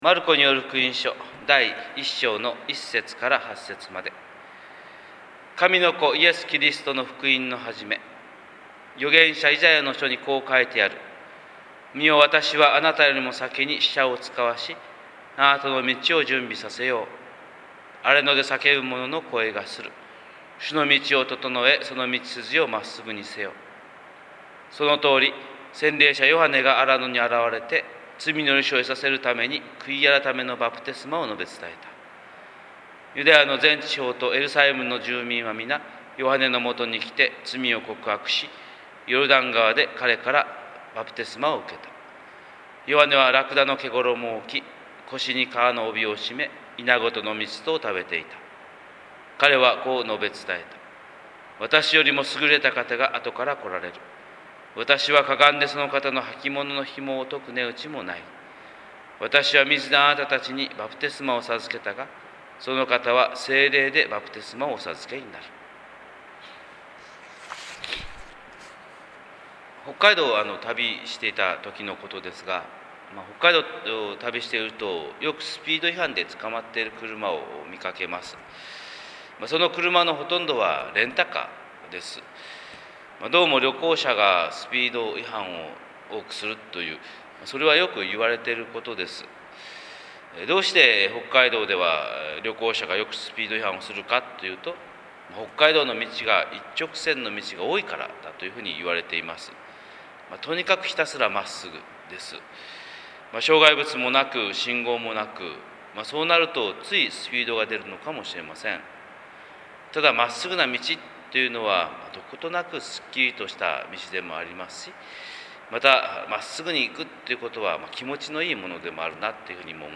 神様の色鉛筆（音声説教）
朝礼拝121218